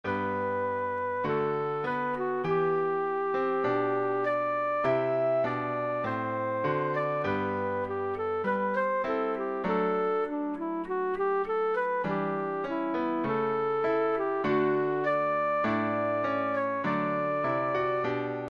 Přednesová skladba pro zobcovou flétnu